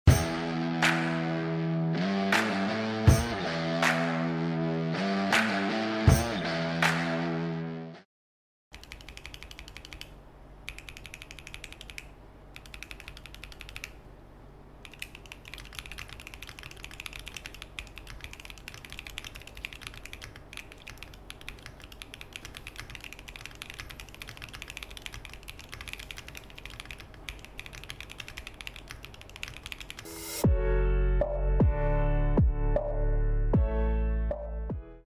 Soundtest switch HMX Taro Balll